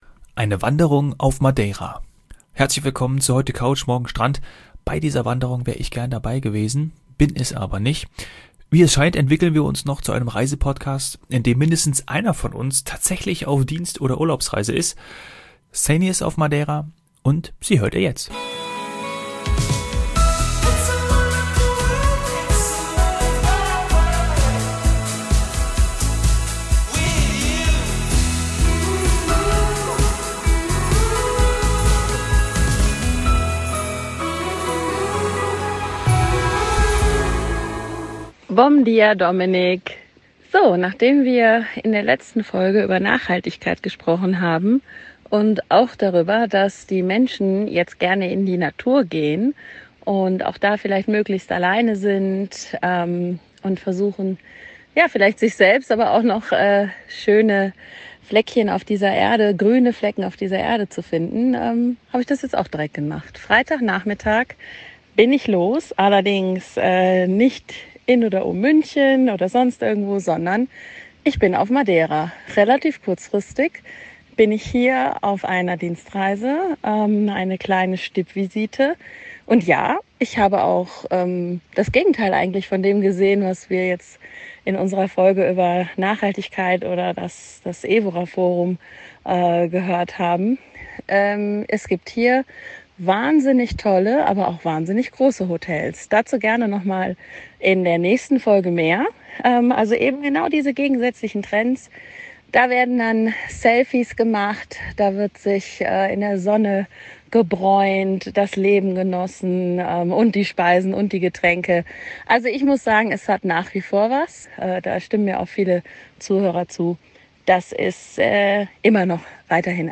Ihr Mikrofon am Headset wollte nicht so recht mitspielen. Sorry für den Ton.